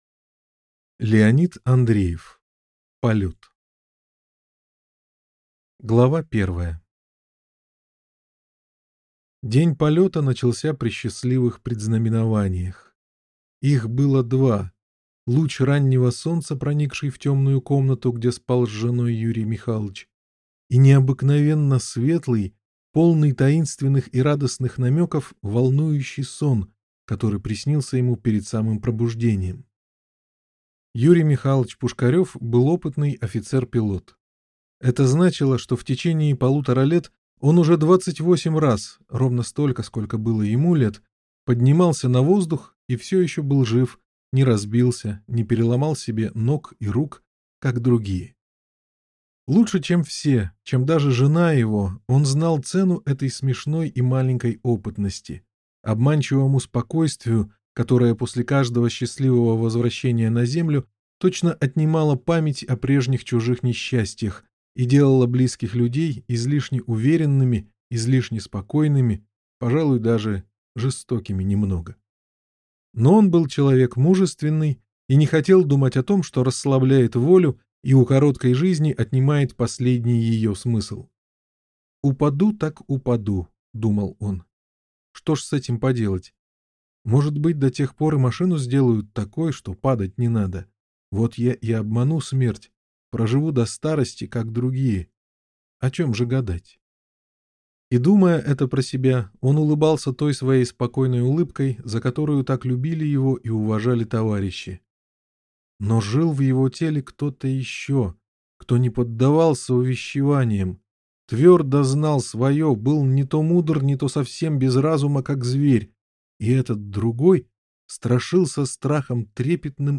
Аудиокнига Полёт | Библиотека аудиокниг